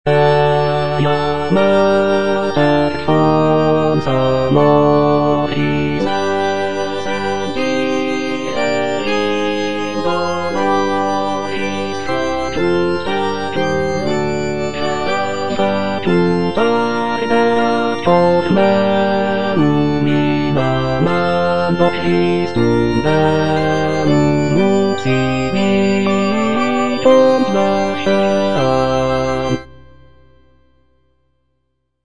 G.P. DA PALESTRINA - STABAT MATER Eja Mater, fons amoris (bass II) (Emphasised voice and other voices) Ads stop: auto-stop
sacred choral work